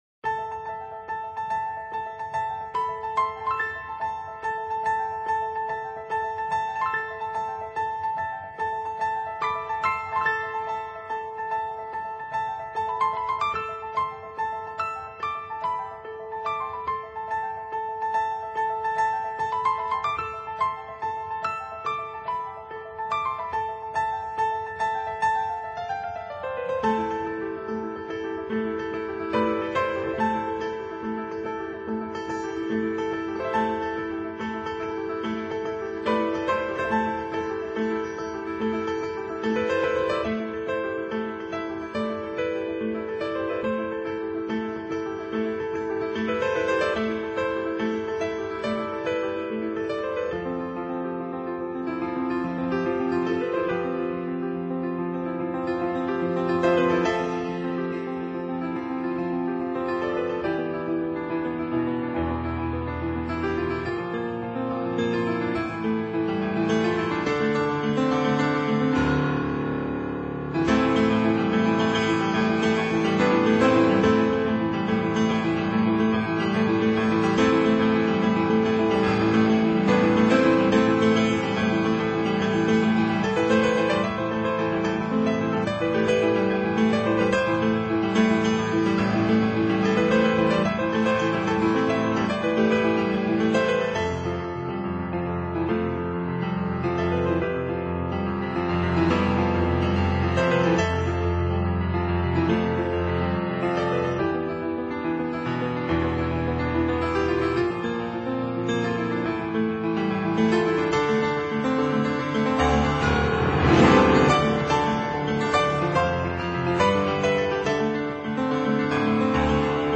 音乐类型：NewAge 新世纪
音乐风格：Piano